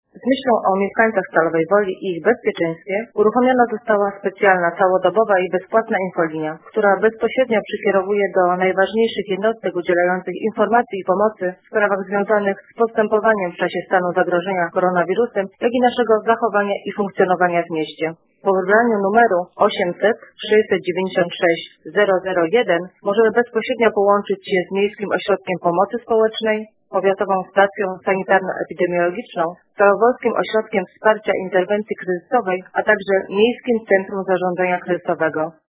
Władze Stalowej Woli uruchomiły bezpłatną infolinię dla mieszkańców miasta. To bezpłatny numer pod którym można sie skontaktować w sprawach związanych z zagrożeniem epidemicznym koronawirusem. Mówi wiceprezydent Stalowej Woli Renata Knap: